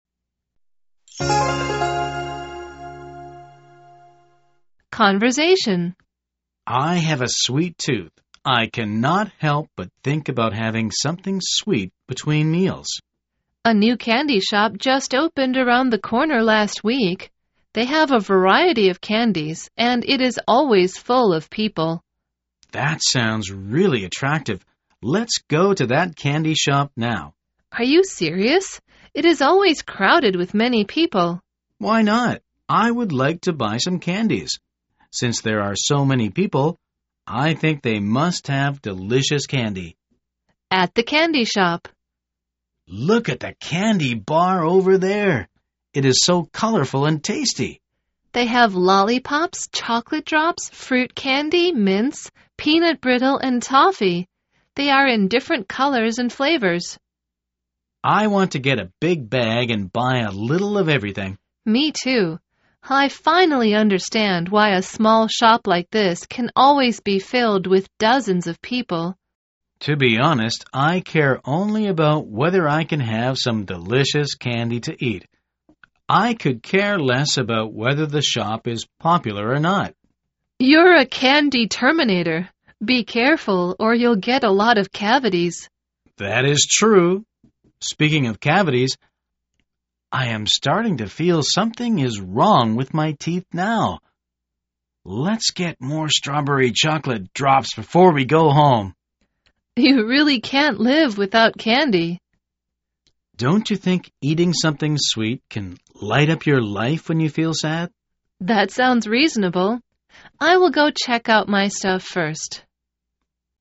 口语会话